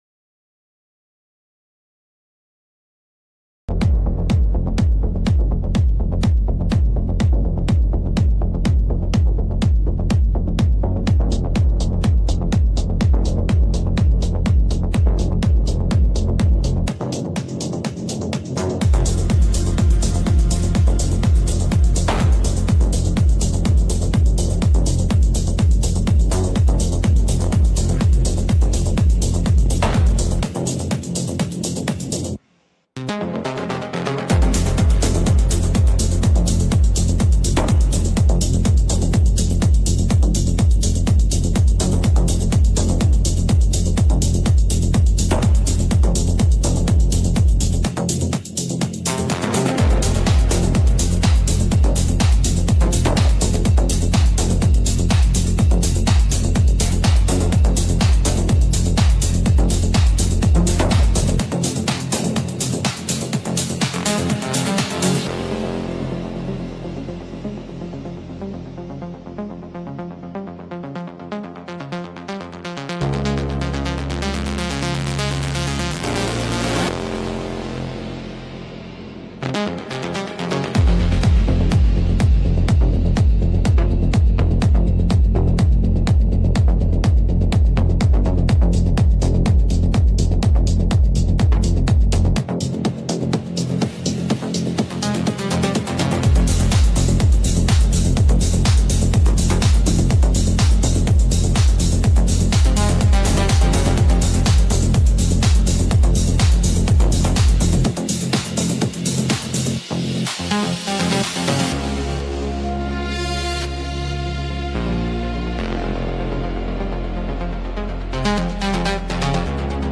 What i streamed on Dlive.....hypnotic dark techno